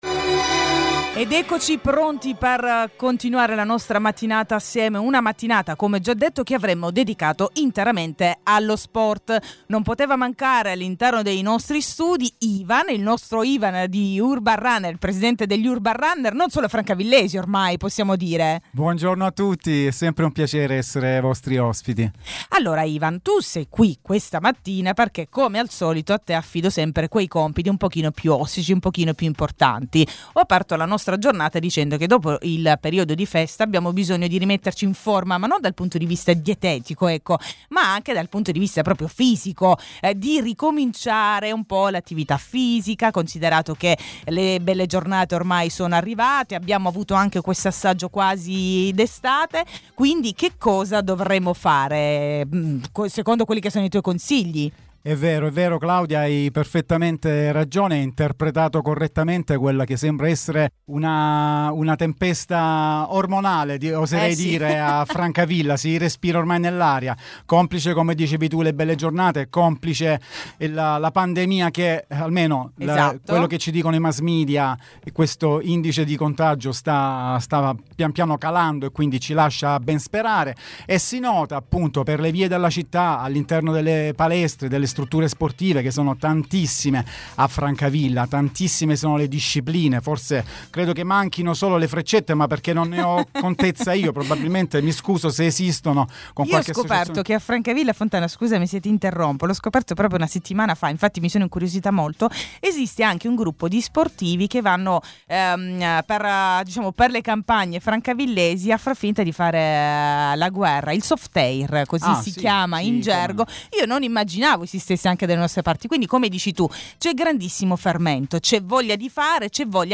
Urban Runner. Progetti, obiettivi e consigli. In studio